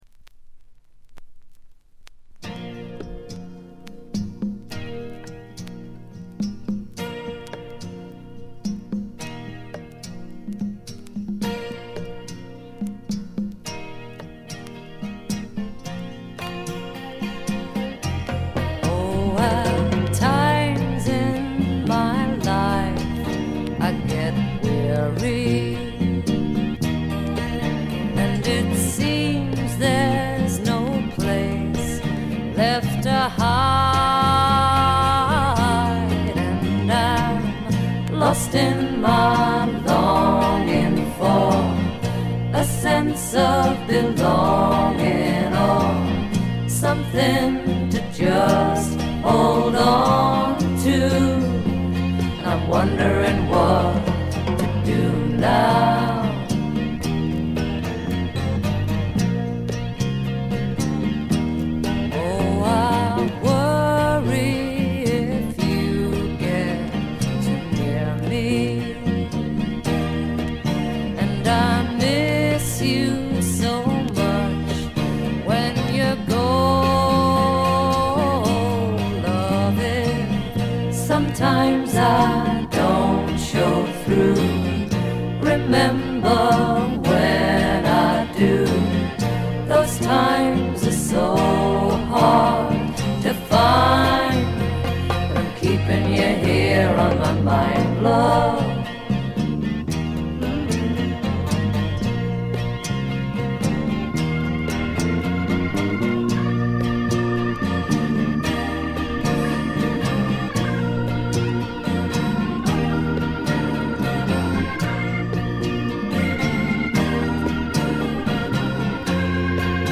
静音部での細かなチリプチ。散発的なプツ音少し。
試聴曲は現品からの取り込み音源です。